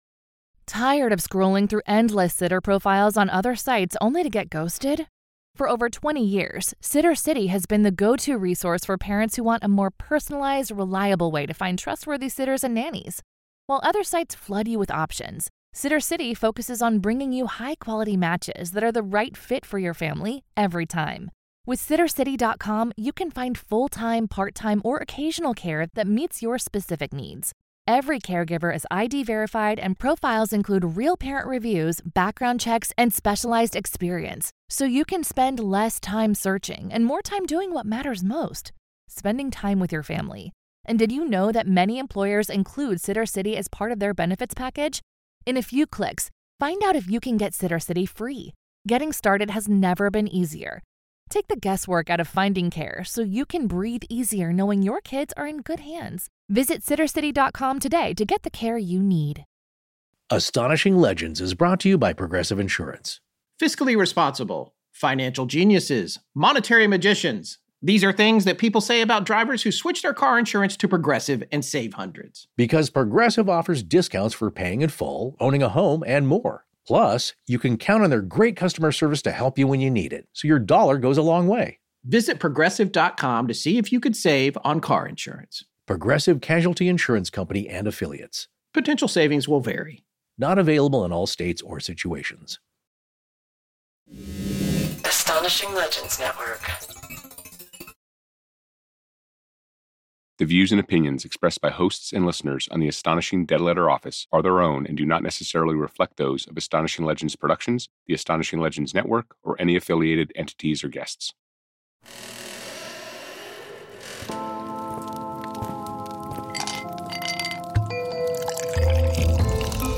read a listener submission